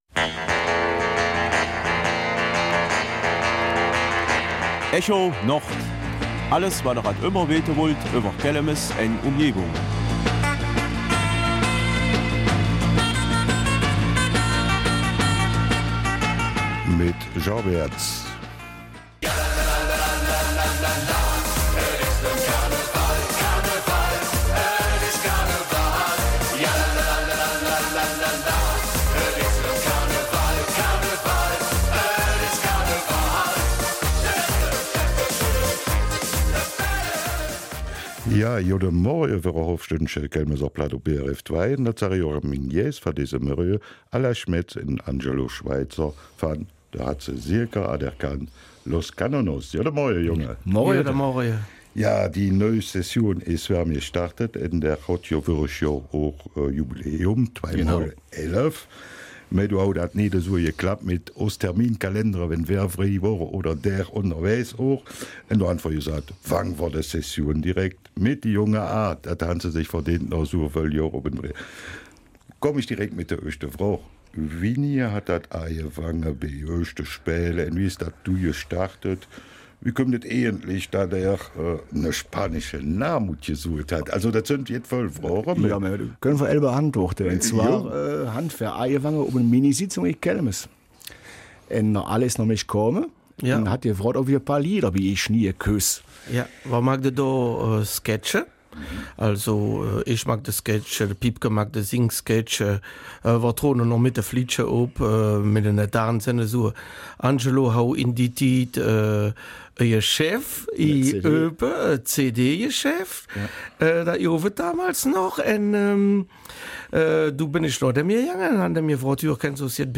Studiogästen